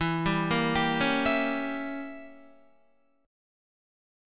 Edim7 chord